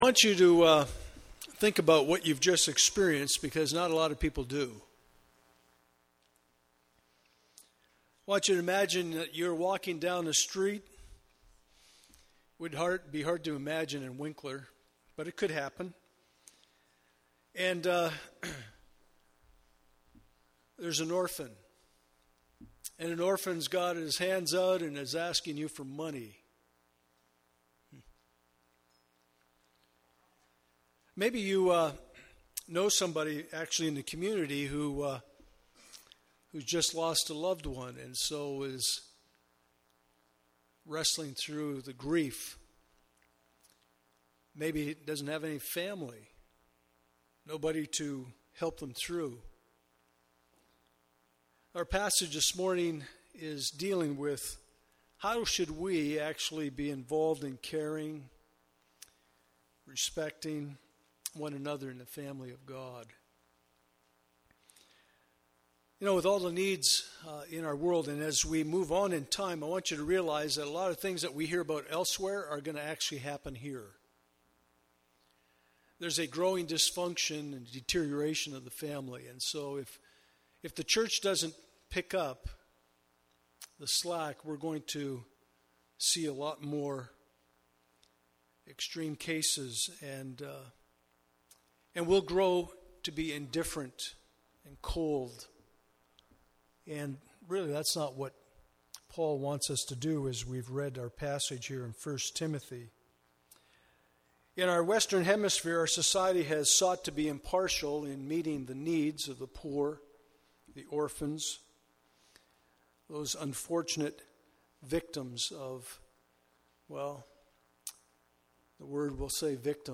Passage: 1 Timothy 5:1-16 Service Type: Sunday Morning « God’s Immutability